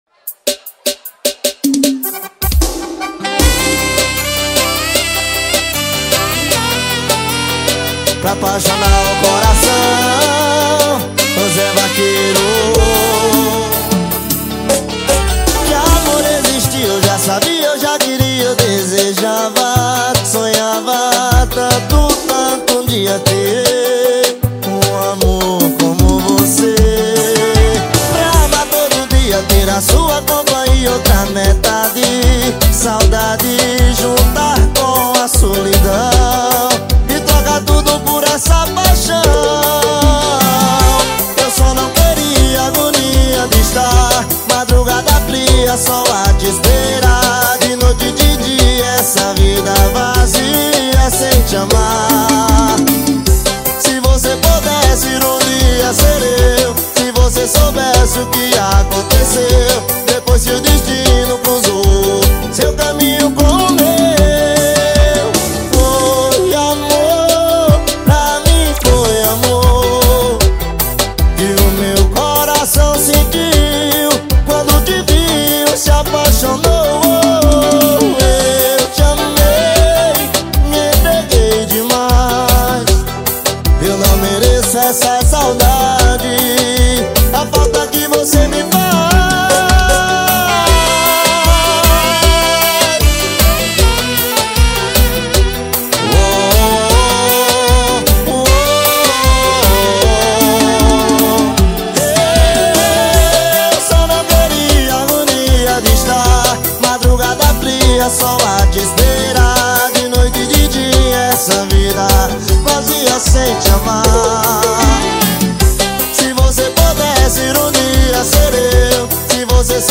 2024-07-20 21:11:42 Gênero: Sertanejo Views